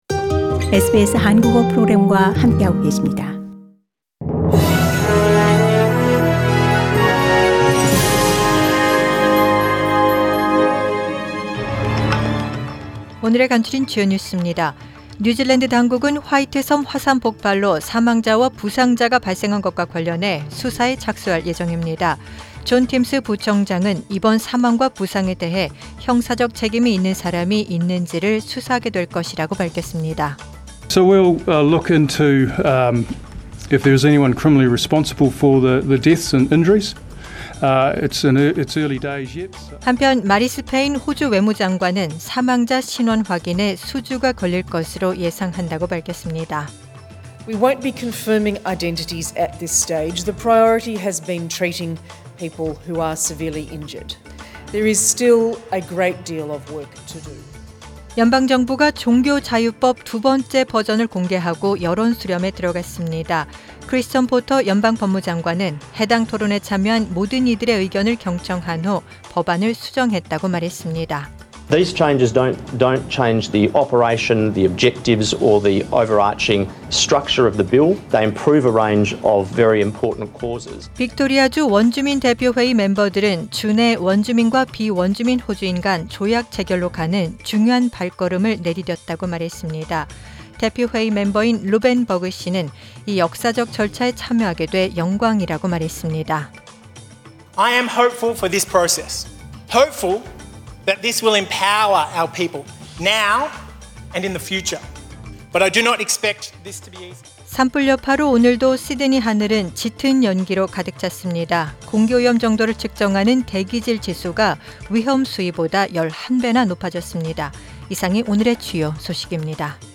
Find out Today’s top news stories on SBS Radio Korean.